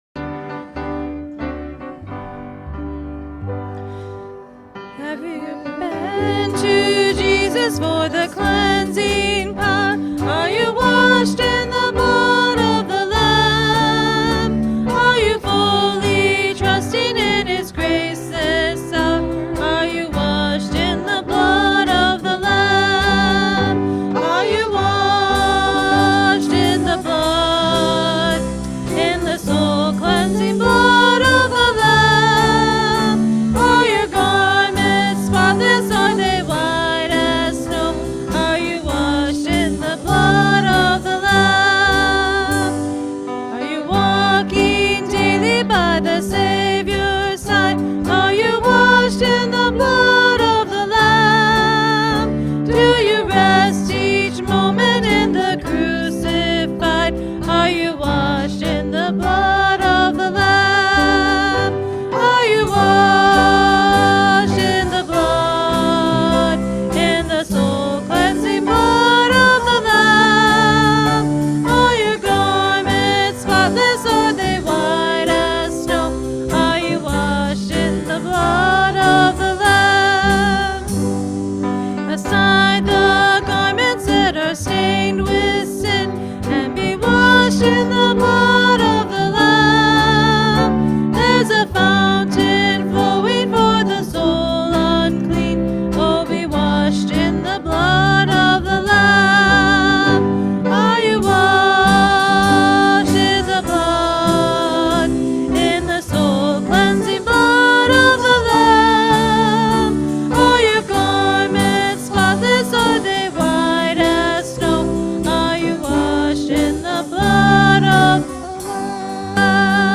Worship-September-3-2023-Voice-Only.mp3